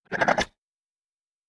Monster_01_Attack.wav